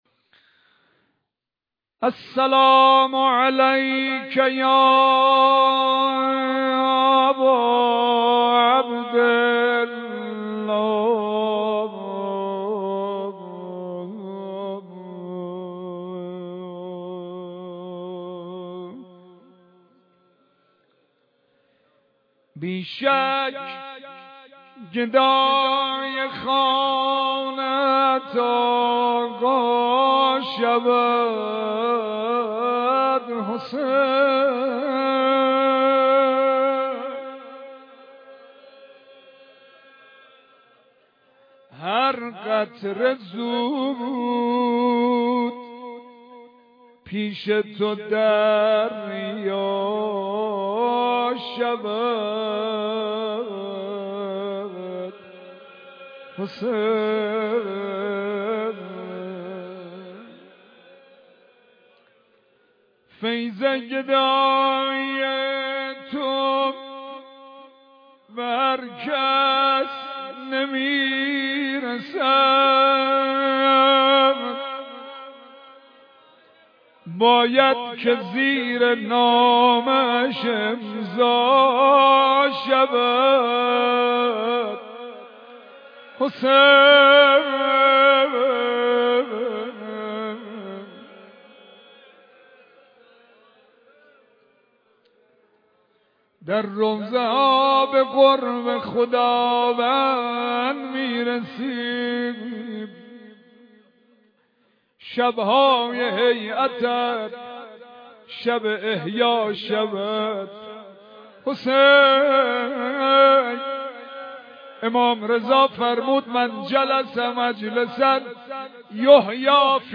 روضه امام سجاد (ع)